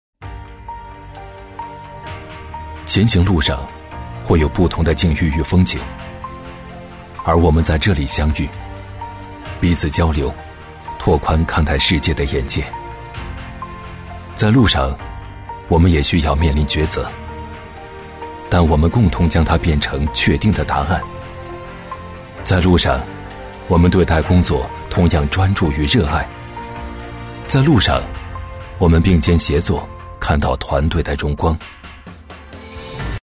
男136-走心旁白《在路上》- 讲述感
男136-大气主流 自然诉说
男136-走心旁白《在路上》- 讲述感.mp3